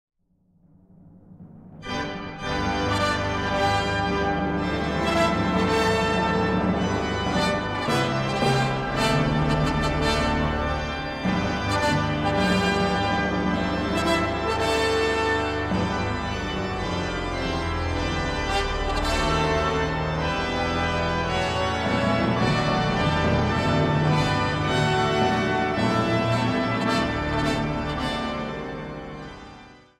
Instrumentaal
Zang